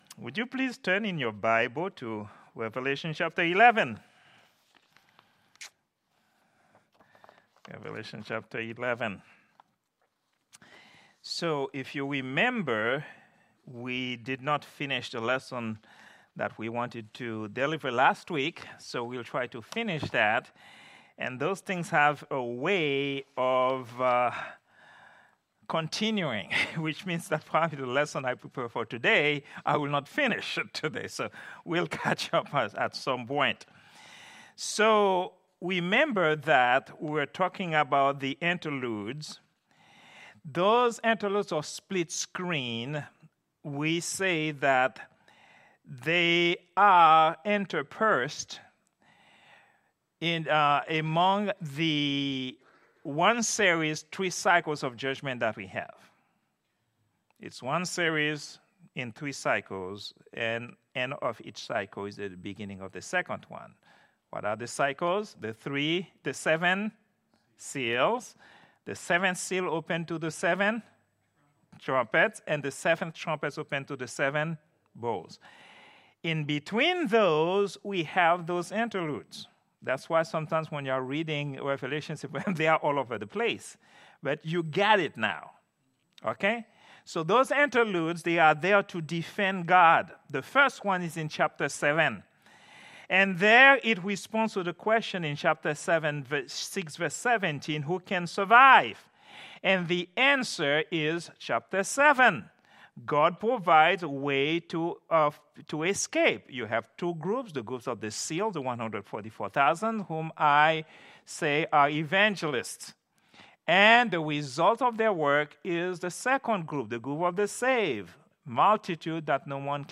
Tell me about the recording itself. Prayer_Meeting_11_06_2024.mp3